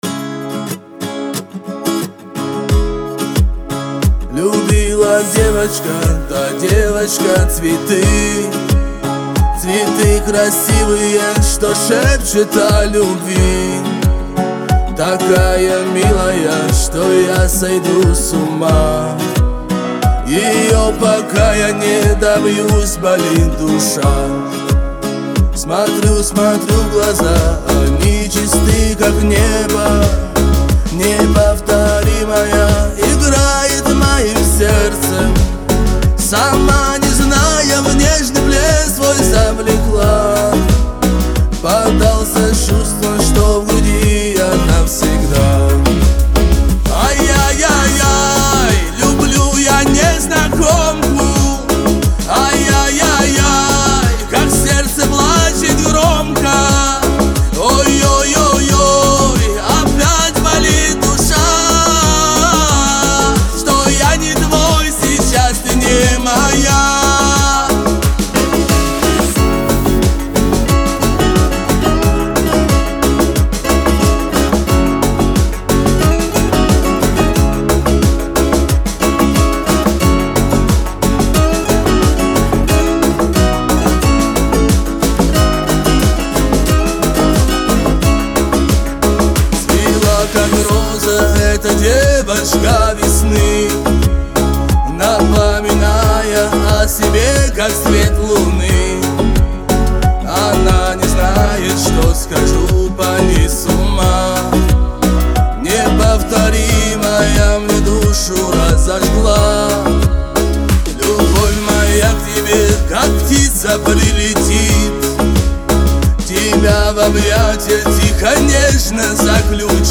это трек в жанре поп с элементами фолка